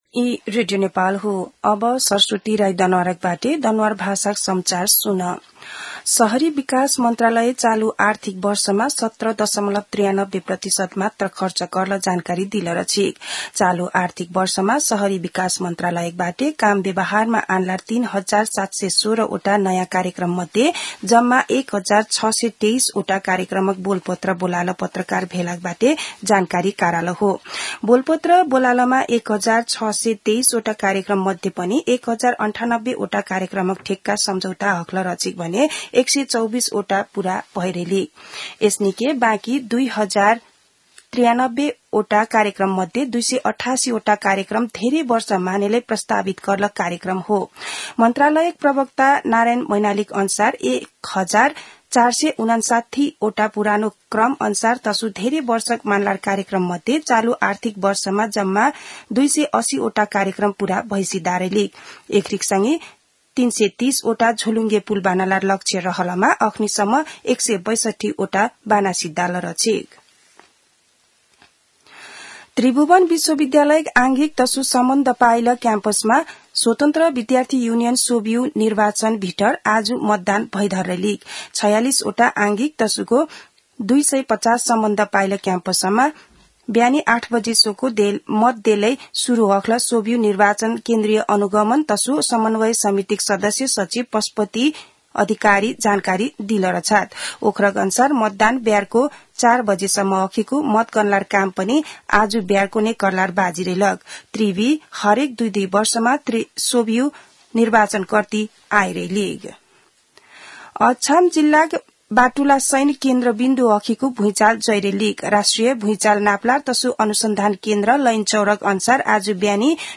दनुवार भाषामा समाचार : ५ चैत , २०८१
danuwar-news-1.mp3